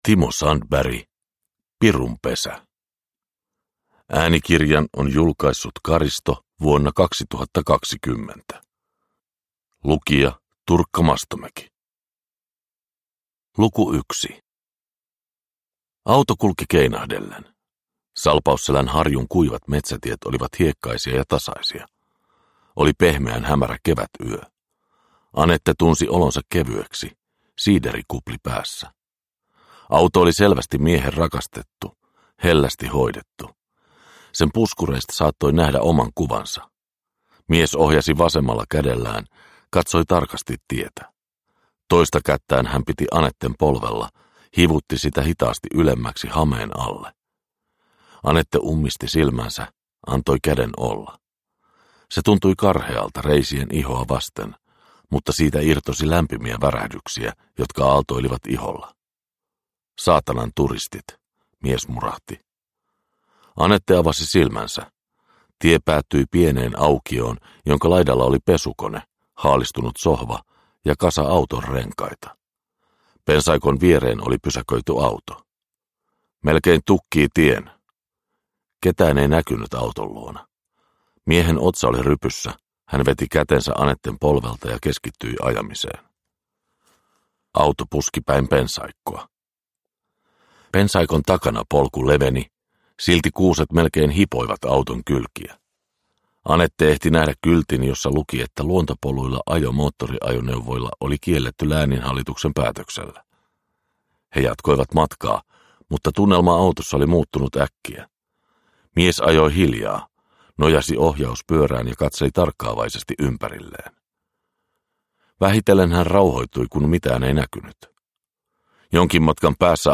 Pirunpesä – Ljudbok – Laddas ner
Produkttyp: Digitala böcker